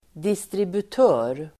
Ladda ner uttalet
Uttal: [distribut'ö:r]